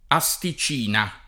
asticina [ a S ti ©& na ] s. f.